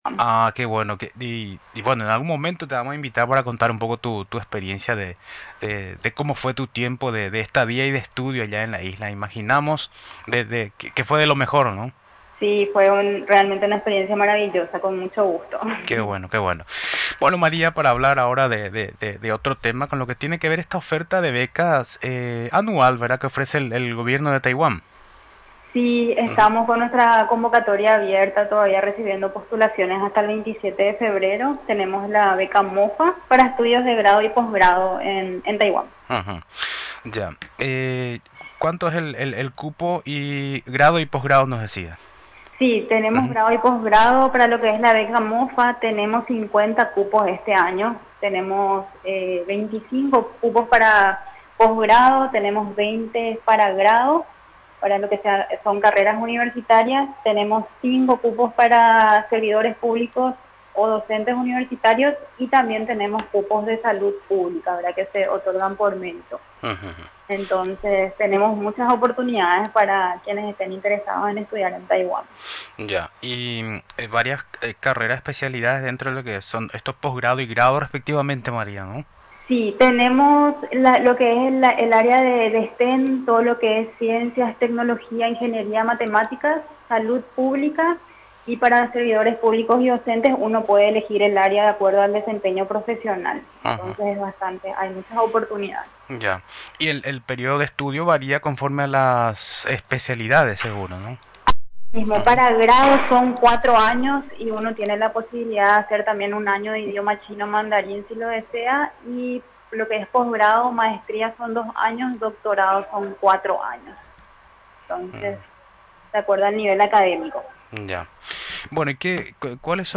Durante la entrevista en Radio Nacional del Paraguay, explicó que se ofrecen cuatro tipos de becas para interesados en estudiar el idioma; en la Universidad Politécnica Taiwán Paraguay; ciencia, tecnología, matemática y agricultura, negocios, ciencias sociales.